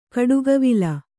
♪ giḍa kāvalu